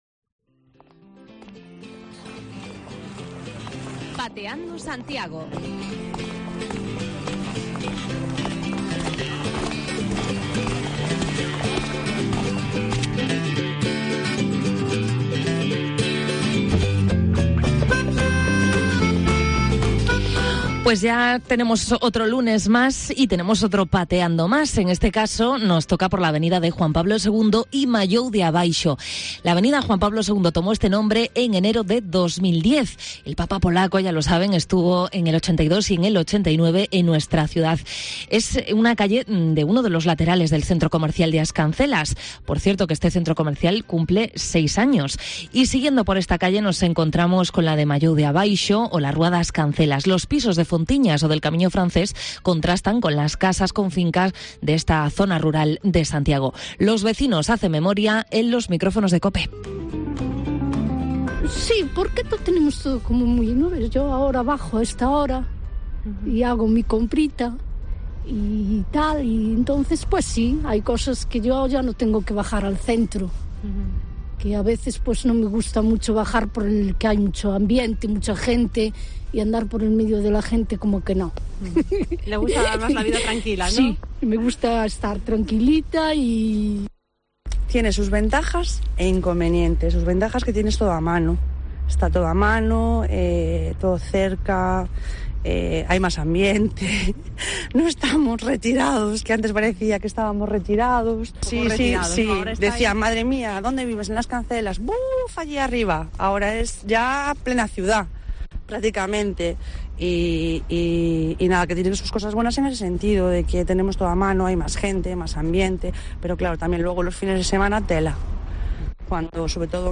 Precios de vivienda más asequibles y que sigue siendo una zona tranquila son las principales ventajas que señalan los vecinos que han participado en este reportaje.